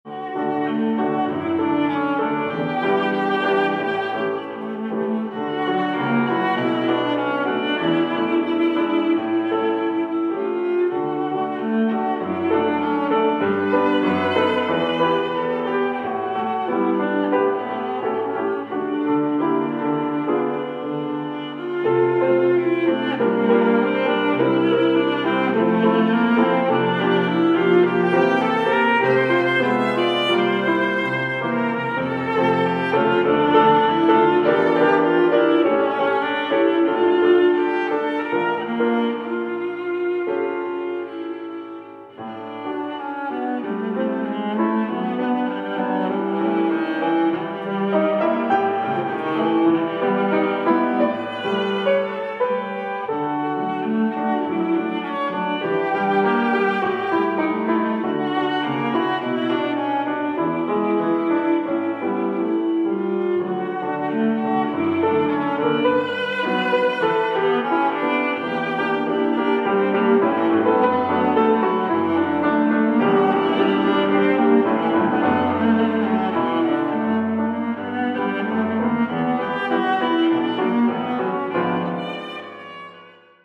(in der Trauerhalle)
klassische Musik